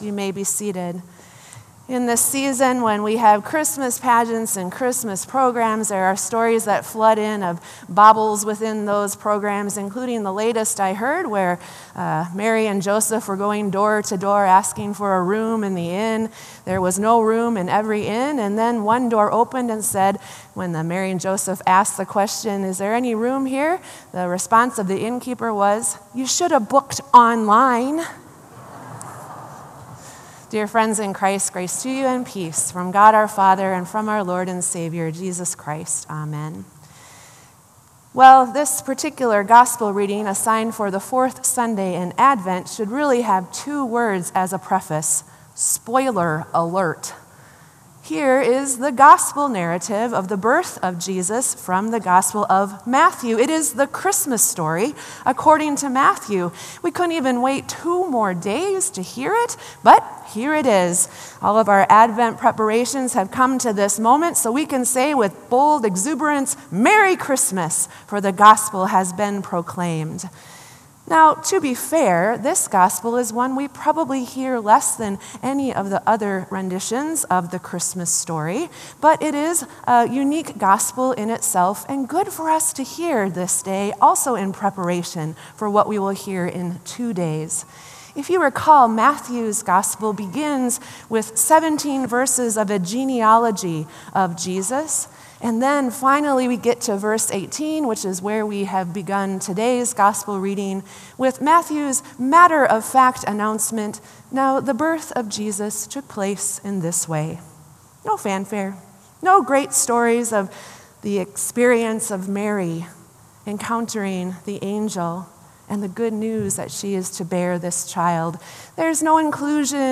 Sermon “A Dream With Direction”